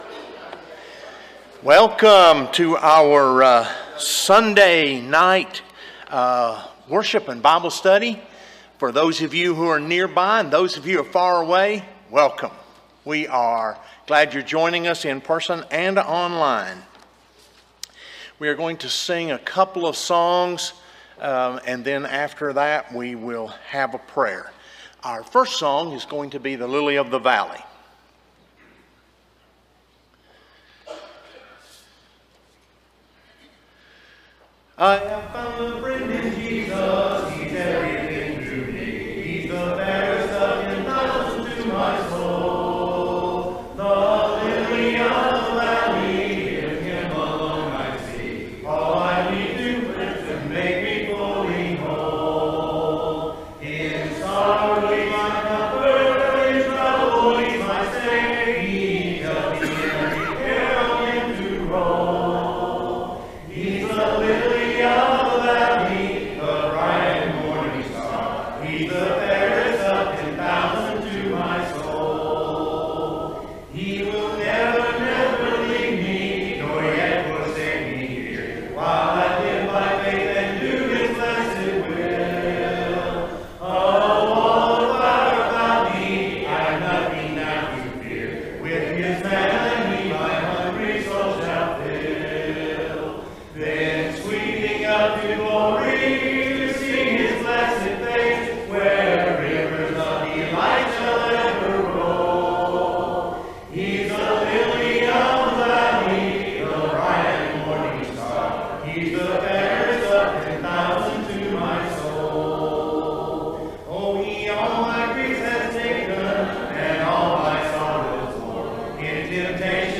Matthew 14:27 (English Standard Version) Series: Sunday PM Service